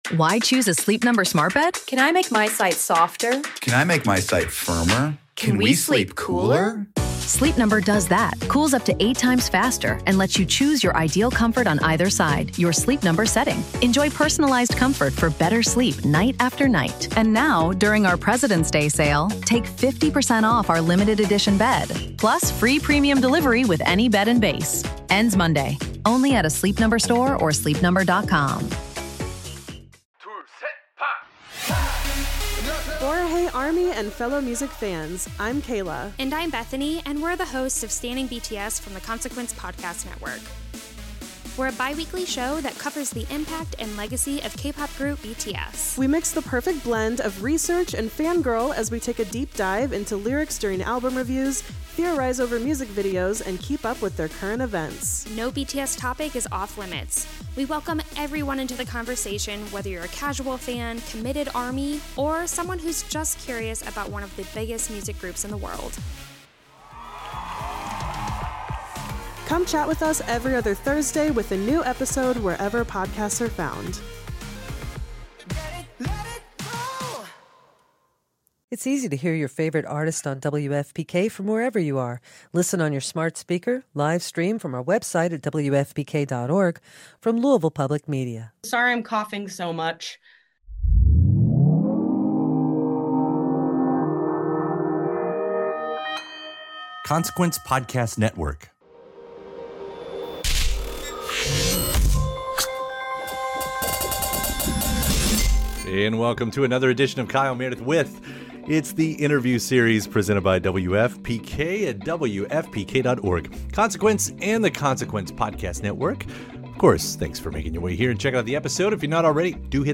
Listen to Haley Lu Richardson chat about all this and more or watch it on YouTube.